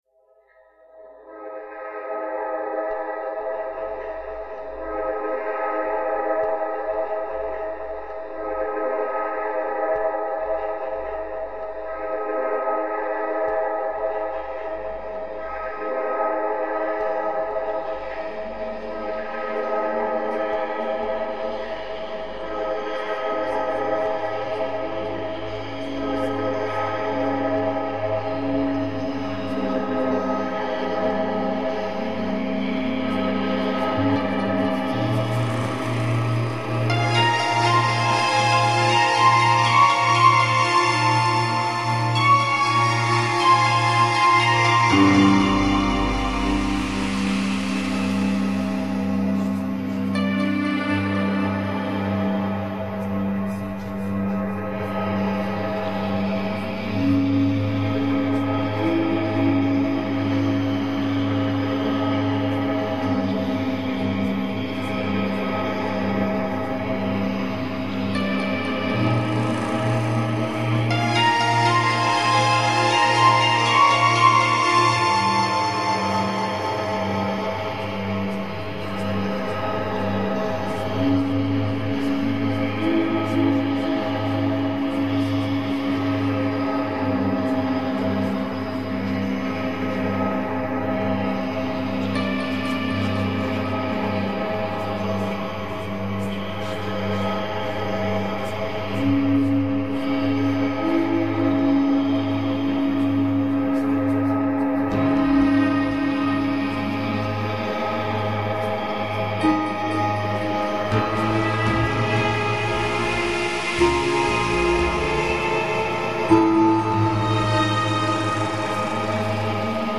Die Ergebnisse erstaunten mich: jedesmal Düsterkeit, egal wie ich an den kleinen Software-Knöpfchen und Reglern drehte.